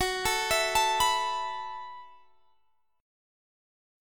Listen to Gbm11 strummed